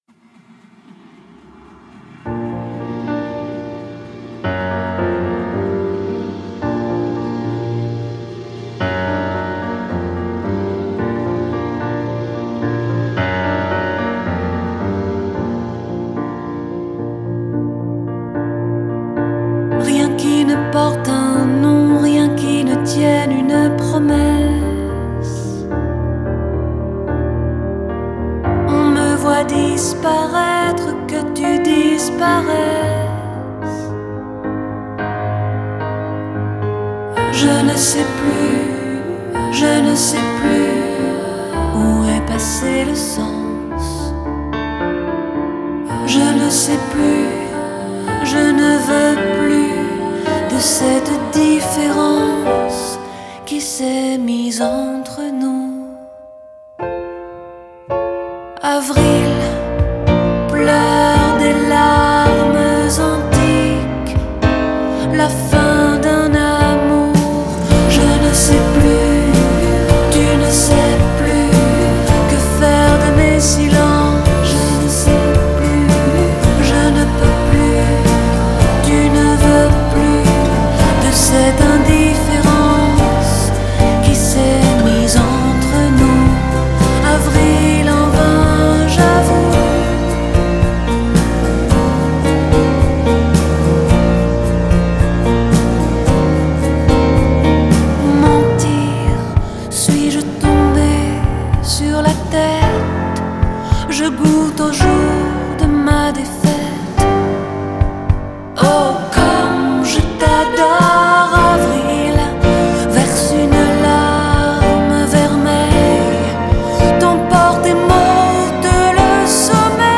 Genre: French Pop, Chanson